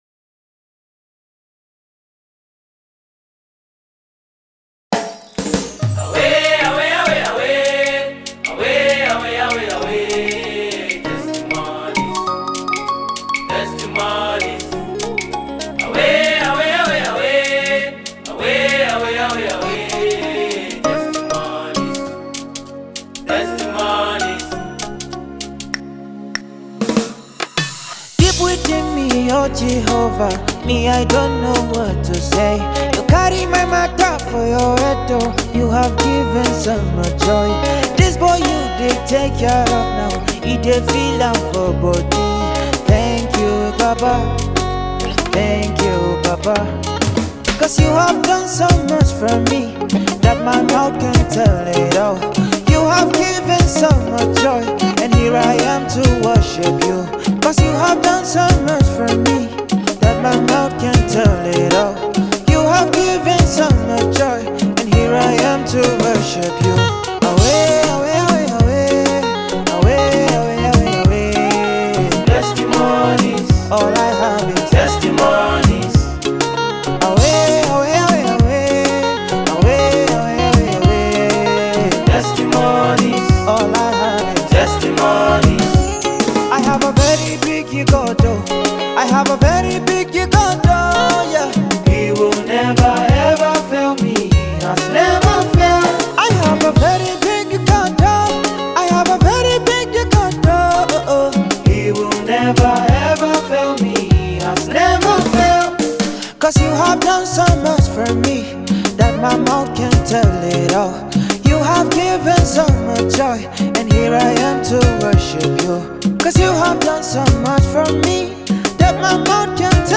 MUSICNaija Gospel Songs